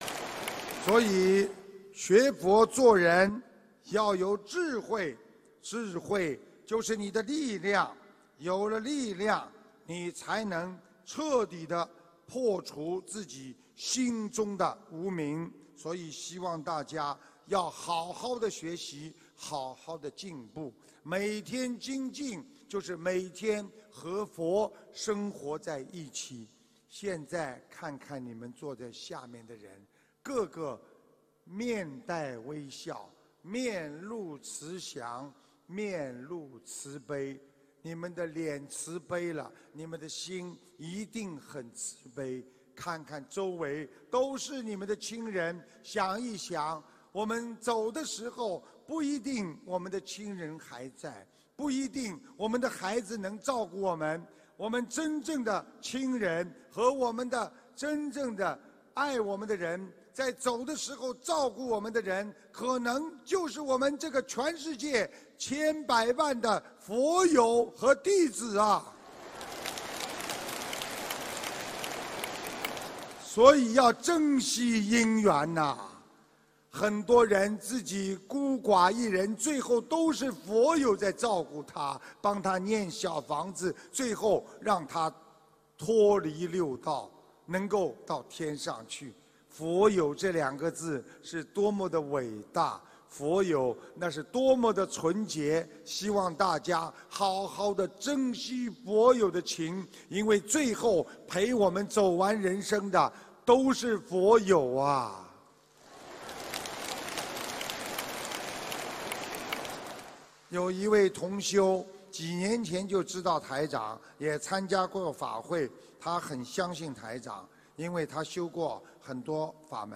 5.听开示